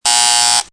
B_SONNETTE.mp3